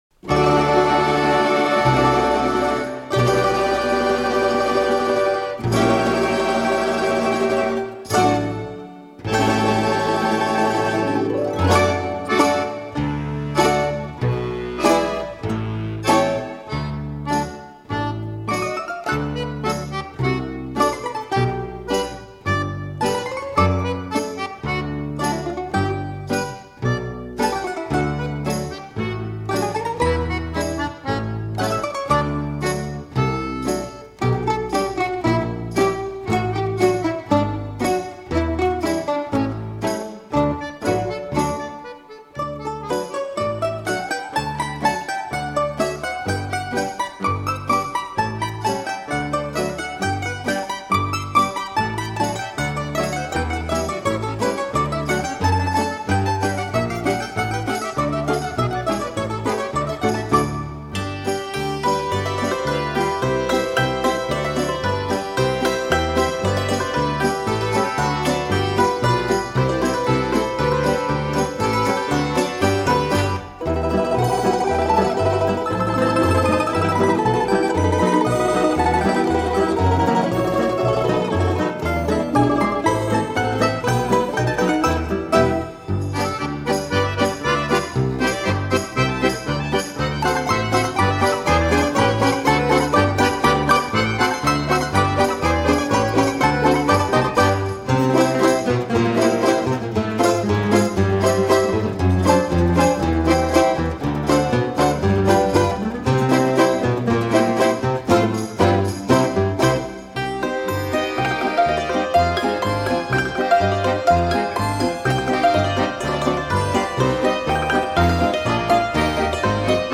Крыжачок  - папулярны беларускі народны танец.